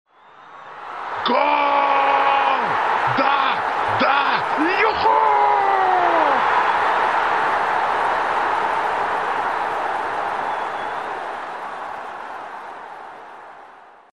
Звуки гол
Гол, да-да, юху